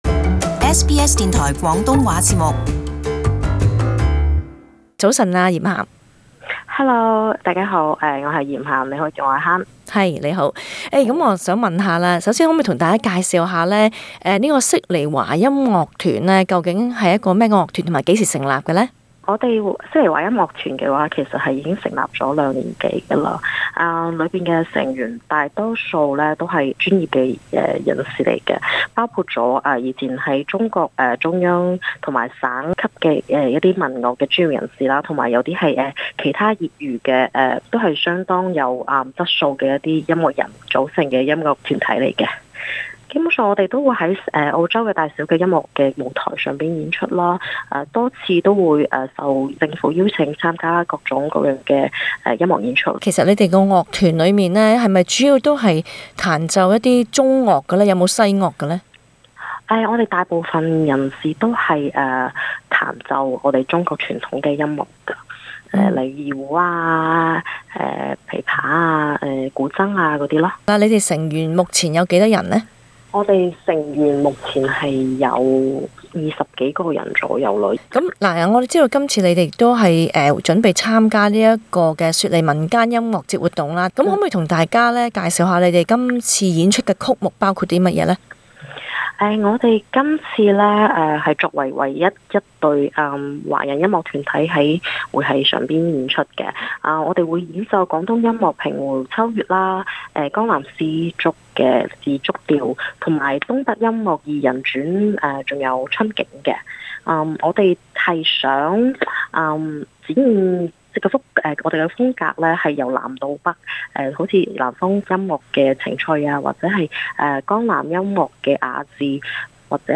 【社區專訪】訪悉尼華音樂團